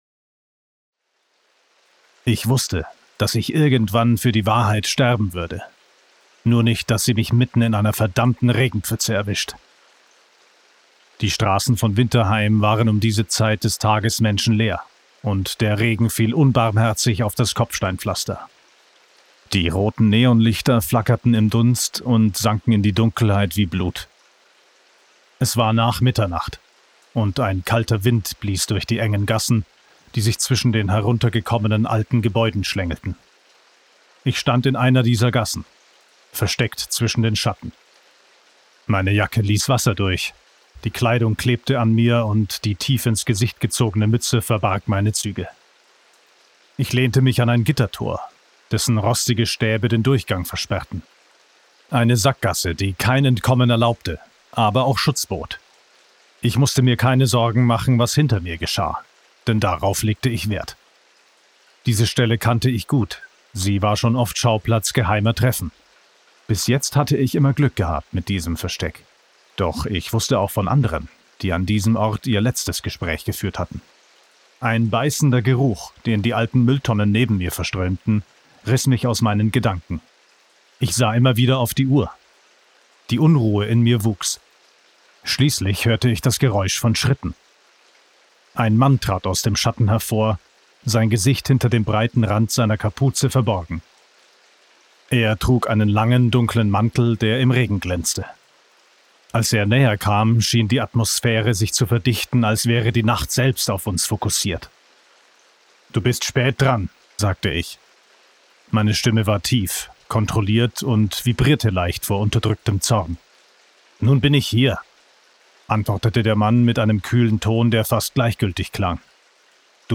Die-letzte-Affaere-Winterheim-Krimi-Hoerprobe-2.mp3